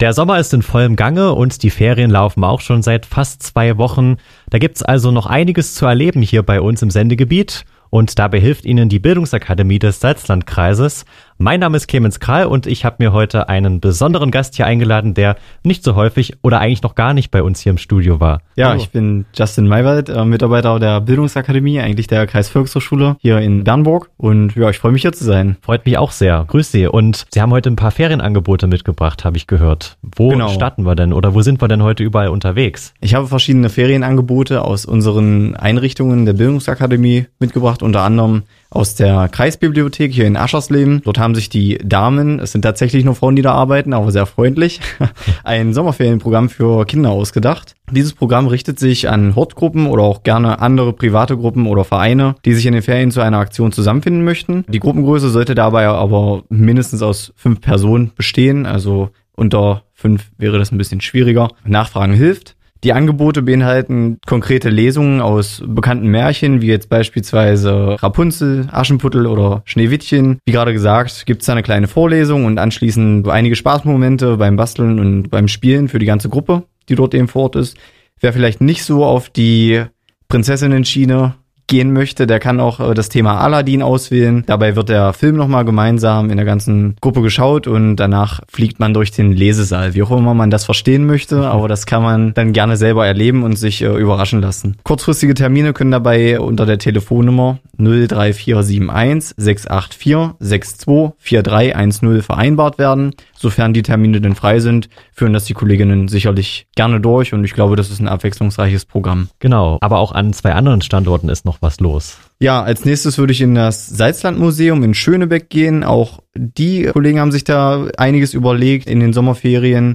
„Radio Salām“ heißt das interkulturelle Magazin auf radio hbw.